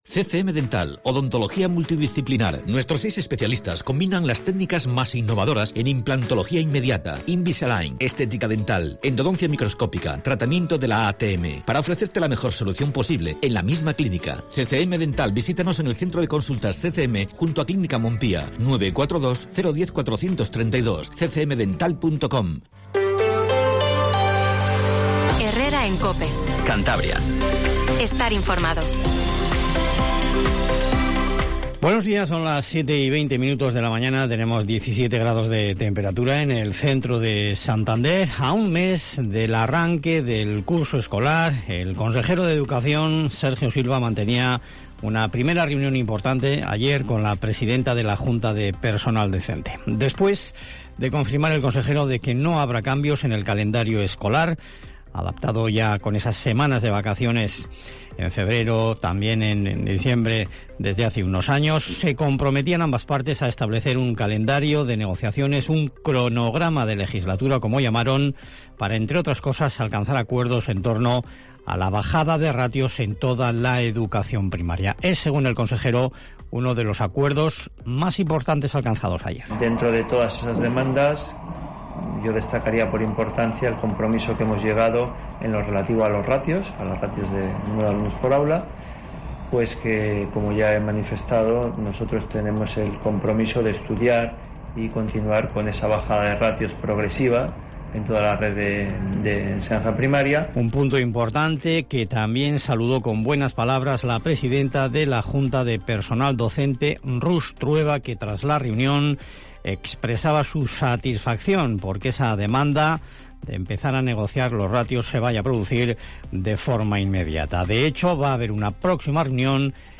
Informativo Matinal Cope 07:20